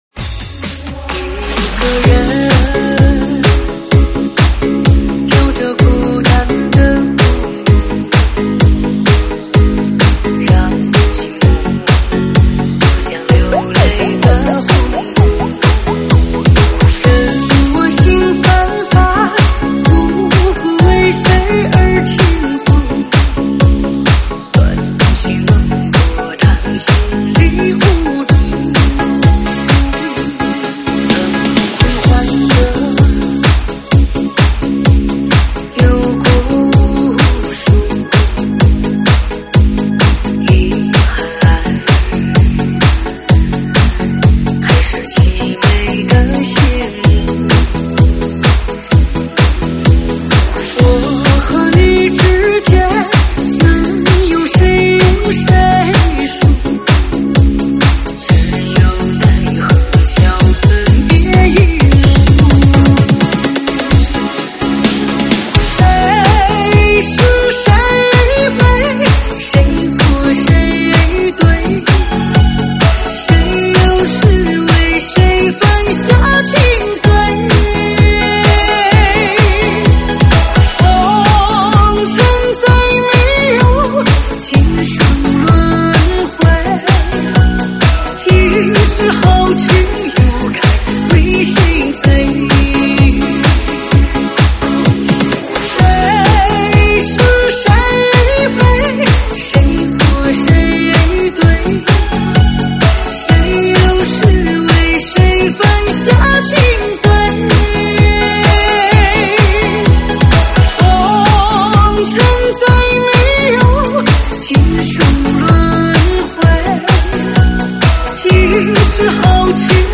舞曲类别：车载大碟